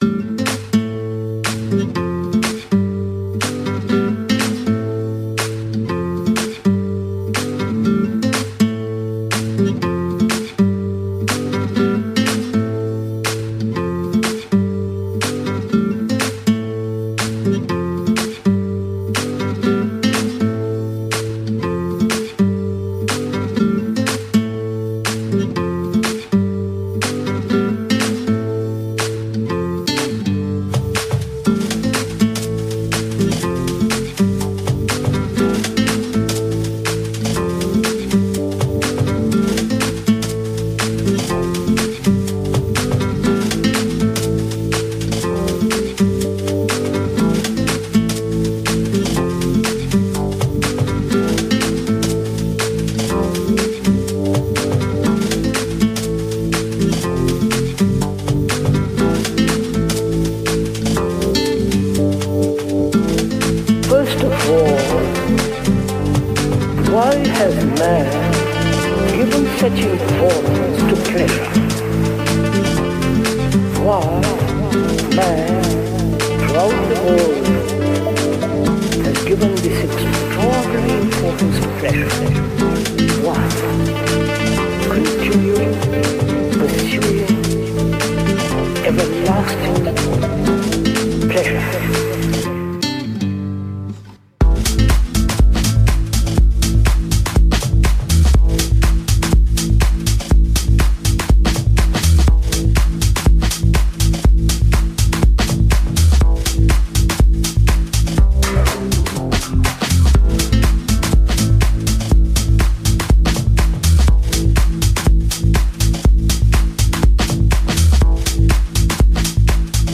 one hour of good sounds